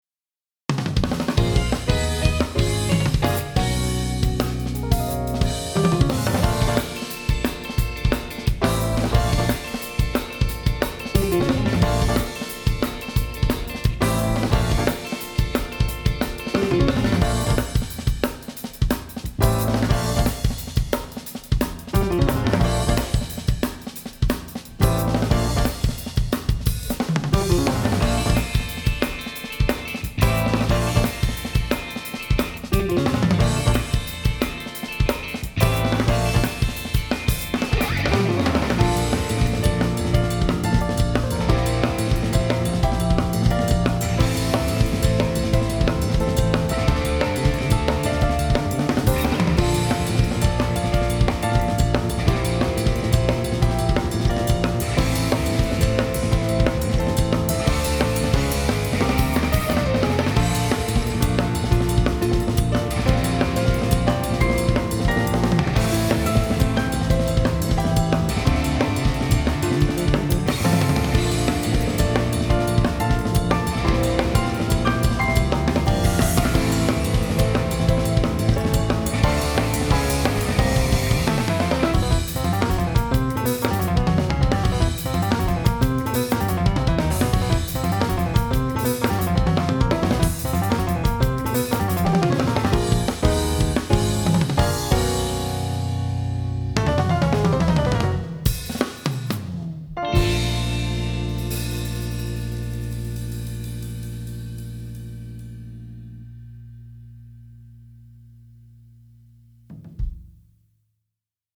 バッキングトラック